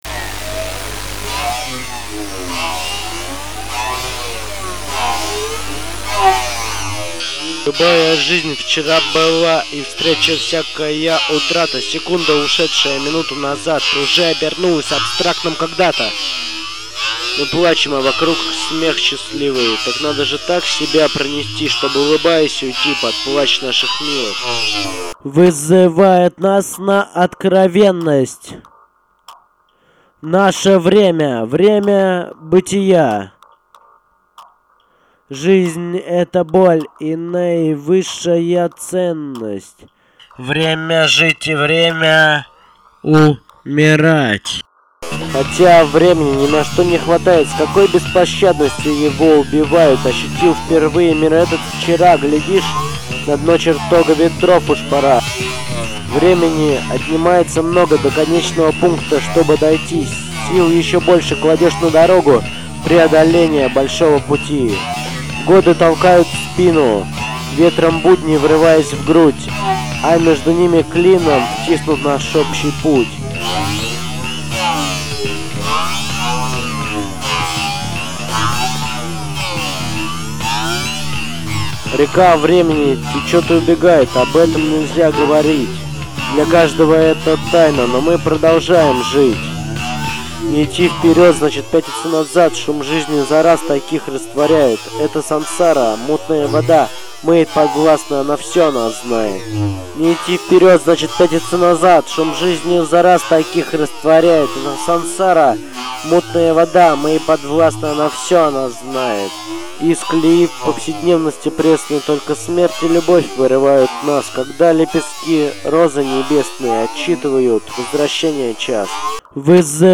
домашней студии